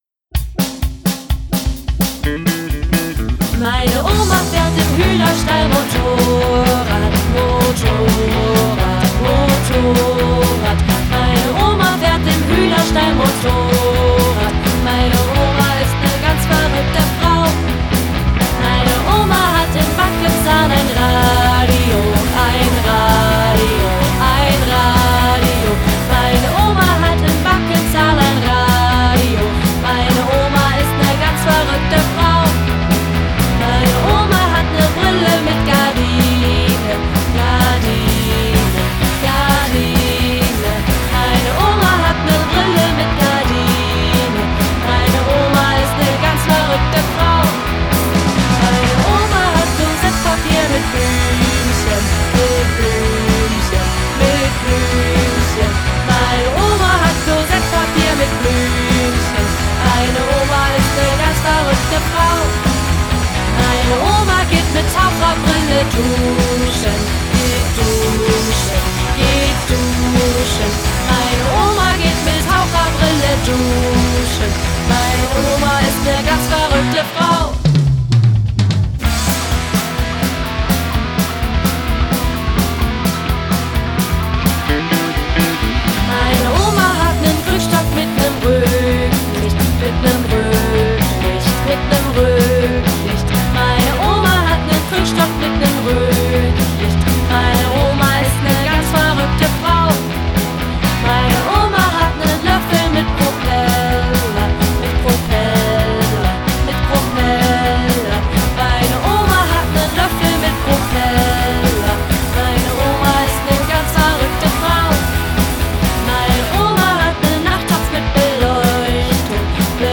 Kinderparty
Anarchie im Hühnerstall, Rock'n'Roll im Kindergarten.